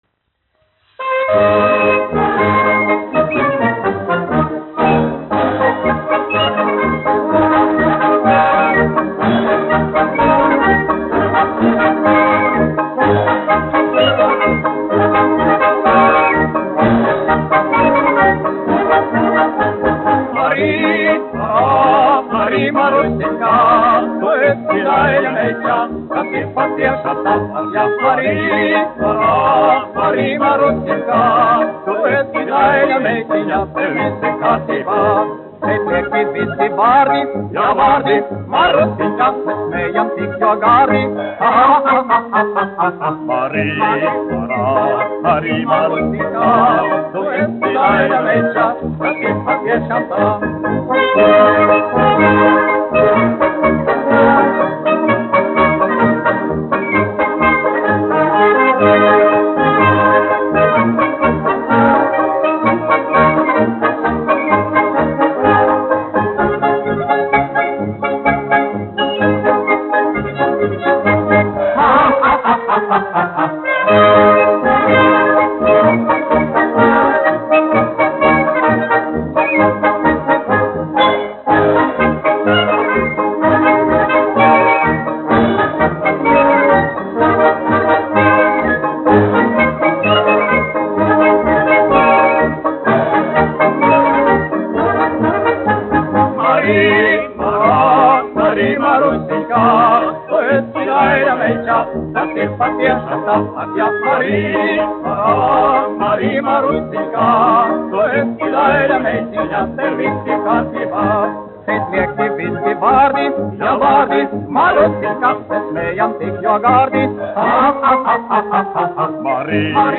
1 skpl. : analogs, 78 apgr/min, mono ; 25 cm
Marši
Fokstroti
Populārā mūzika
Skaņuplate